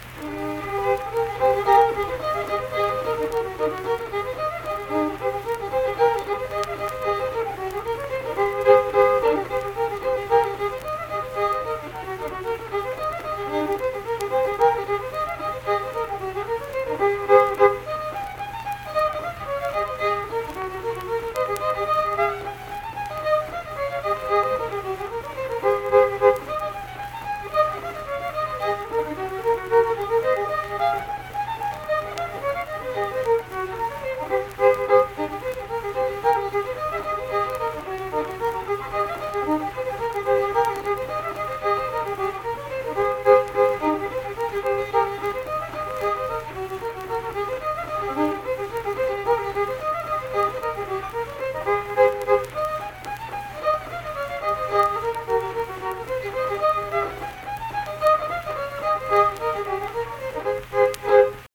Unaccompanied fiddle music
Instrumental Music
Fiddle
Pleasants County (W. Va.), Saint Marys (W. Va.)